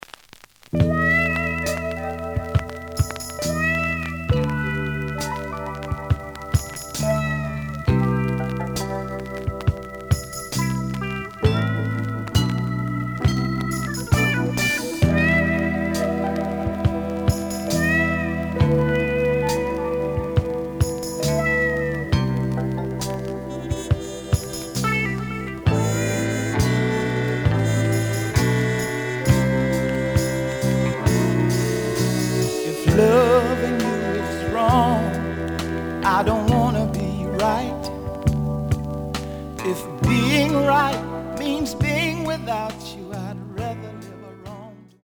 The listen sample is recorded from the actual item.
●Genre: Soul, 70's Soul